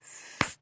Bubble Gum, Blowing Bubbles & Popping.